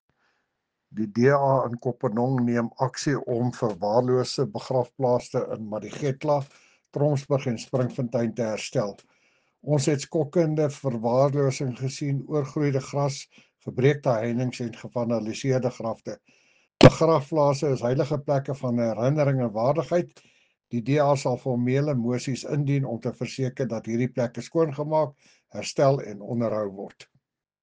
Afrikaans soundbites by Cllr Jacques van Rensburg and Sesotho soundbite by Cllr Kabelo Moreeng.